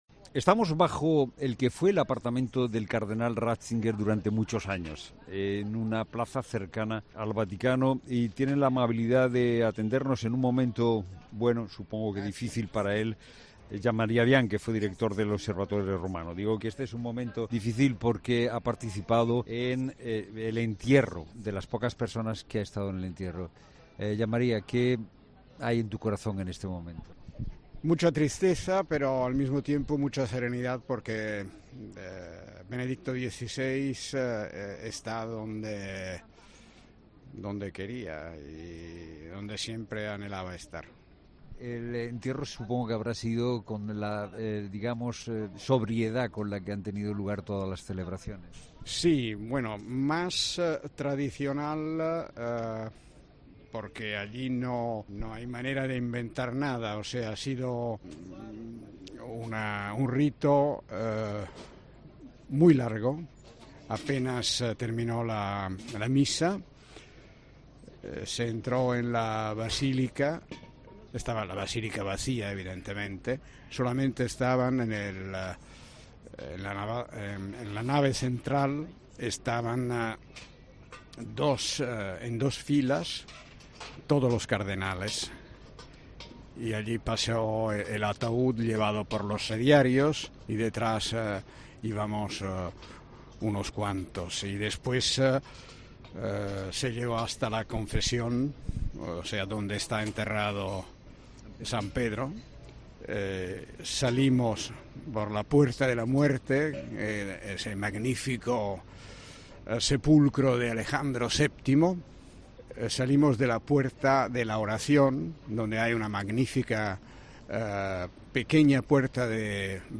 'La Tarde' hace su programa de este jueves 5 de enero desde Roma .